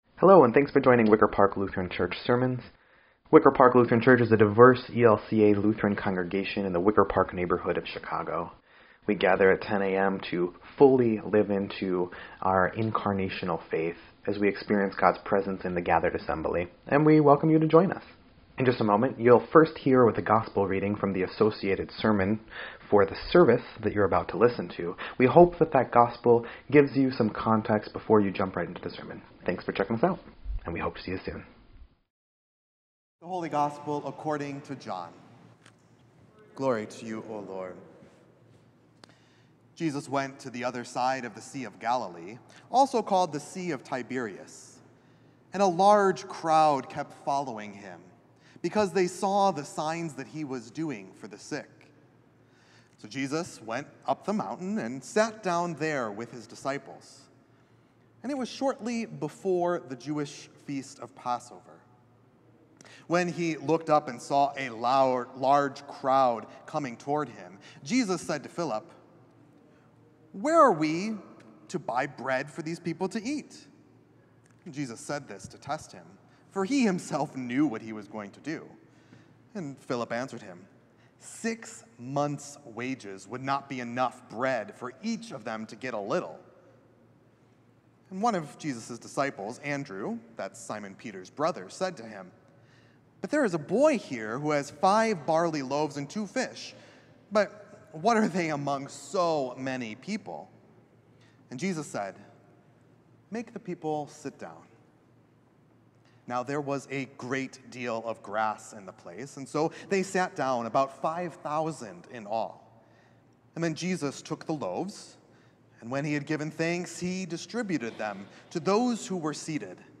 7.28.24-Sermon_EDIT.mp3